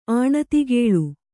♪ āṇatigēḷu